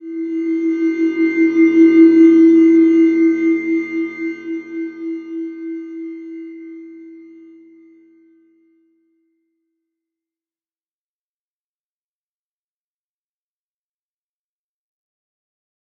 Slow-Distant-Chime-E4-f.wav